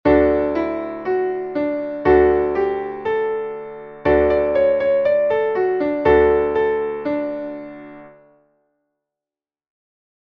Traditionelles Winterlied (Kanon)